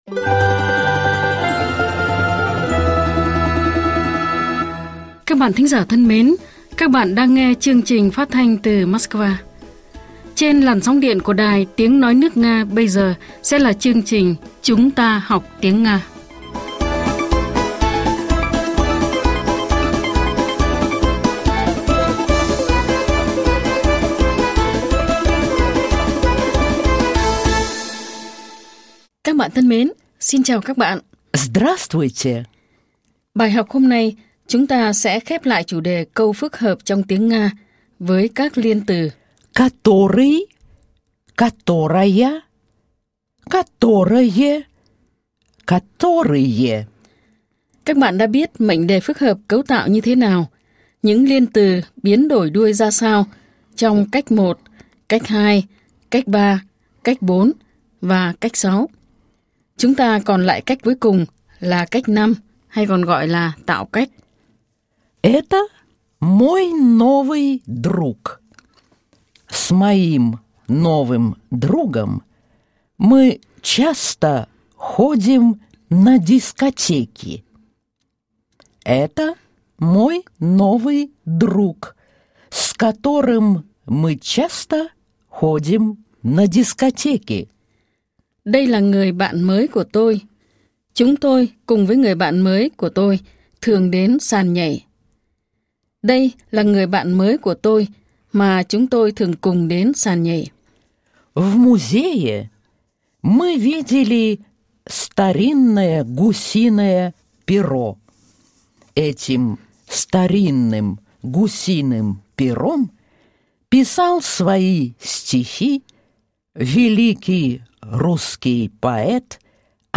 Nguồn: Chuyên mục “Chúng ta học tiếng Nga” đài phát thanh  Sputnik